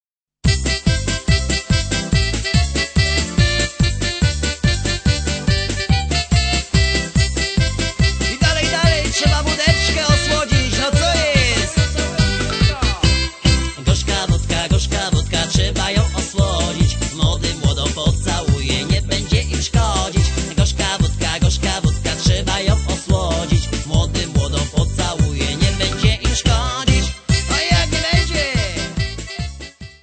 3 CD set of Polish Folk Songs.